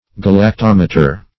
Search Result for " galactometer" : The Collaborative International Dictionary of English v.0.48: Galactometer \Gal`ac*tom"e*ter\, n. [Gr.